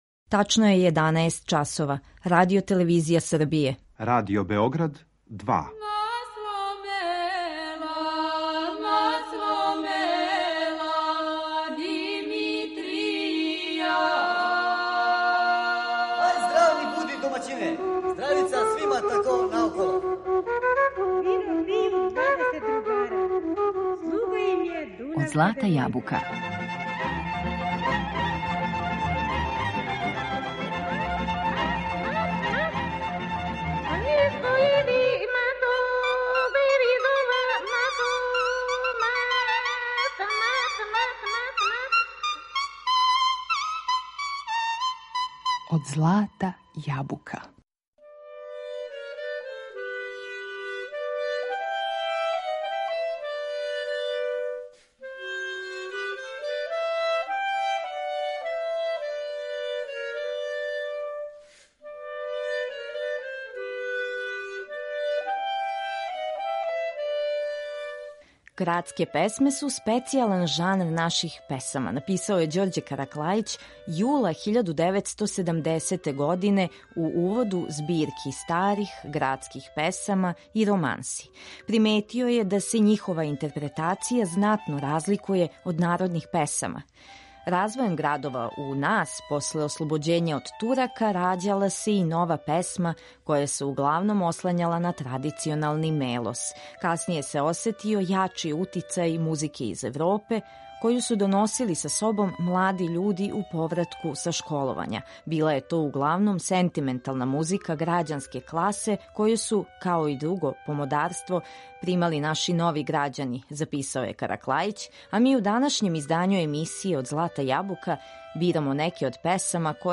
у извођењу вокалних солиста уз пратњу Народног оркестра